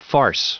Prononciation du mot farce en anglais (fichier audio)
farce.wav